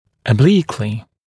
[ə’bliːklɪ][э’бли:кли]наклонно, наискось, под углом